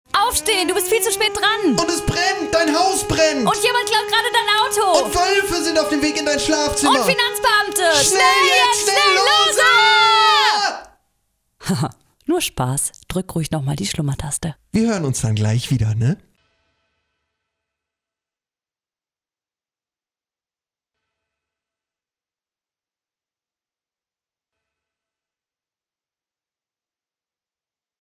Alarm-Wecker
alarm-wecker.mp3